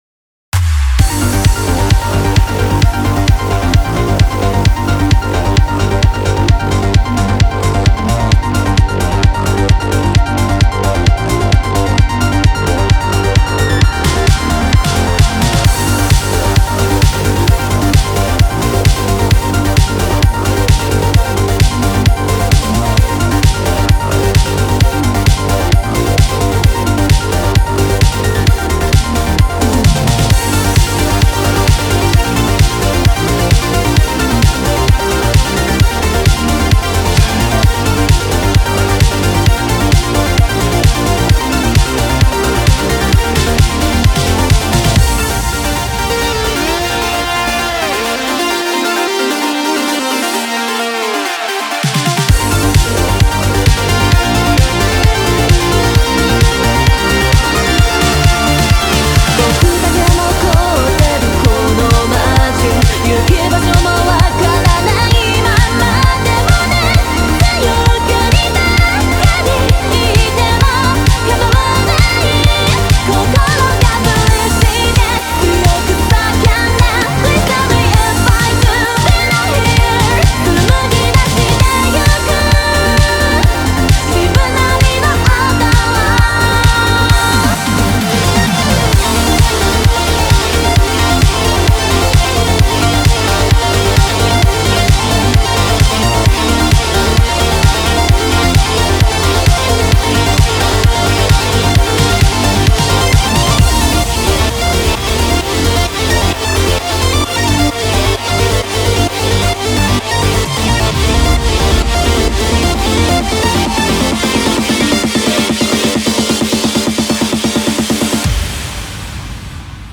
BPM131
コメント[RETRO STYLE ELECTRO]